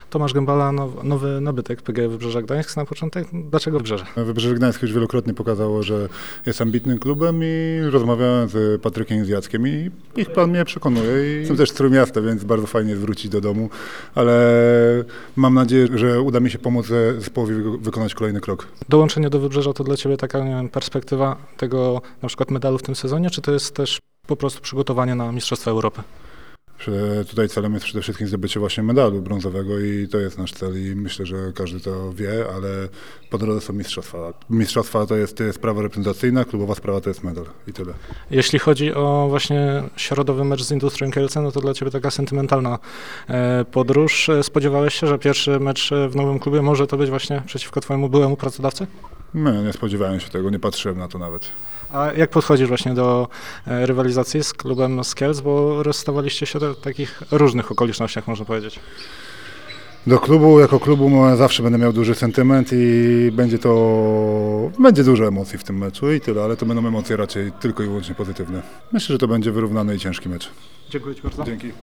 Nowy nabytek Wybrzeża rozmawiał z Radiem Gdańsk tuż po podpisaniu kontraktu.